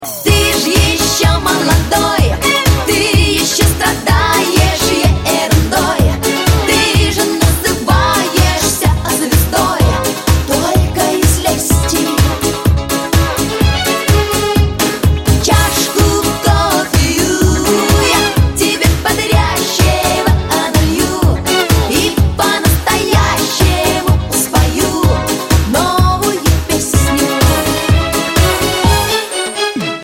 Рингтоны Дискотека 90х
Поп Рингтоны